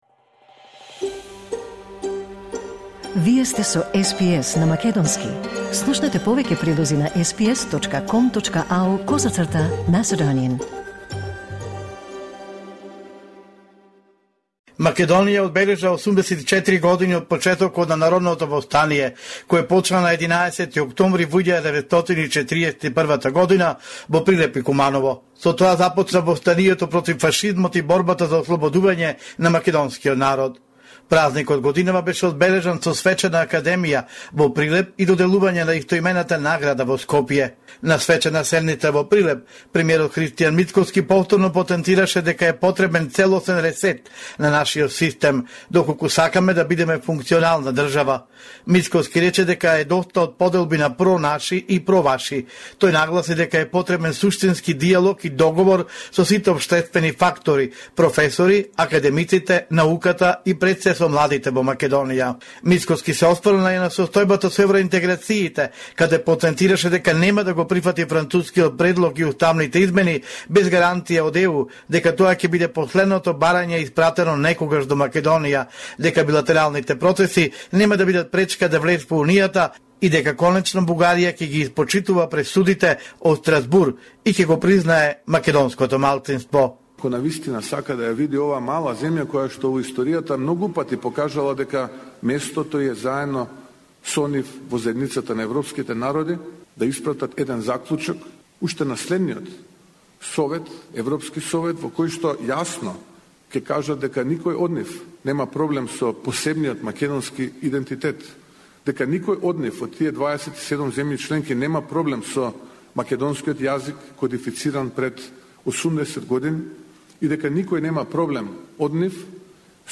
Извештај од Македонија 13 октомври 2025